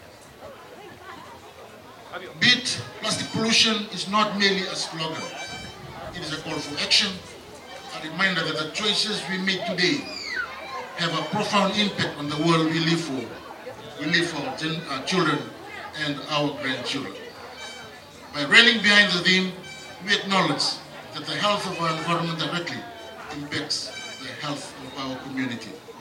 Speaking during the launch in Syria Park in Nausori yesterday, Minister for Local Government Maciu Nalumisa emphasized that embracing sustainable practices and reducing plastic waste will ensure a better quality of life for all and the future generation.
Local Government Minister, Maciu Nalumisa.